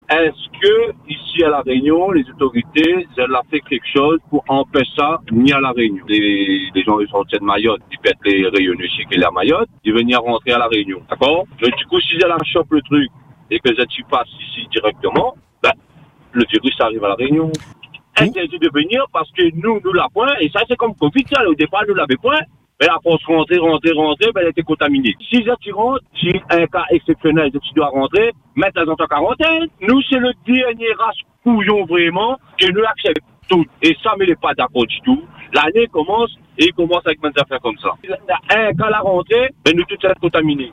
Une situation qui interroge cet auditeur, inquiet pour l’île.
Une réaction à chaud, dictée par la peur d’une propagation rapide et par le sentiment que La Réunion pourrait être prise de court.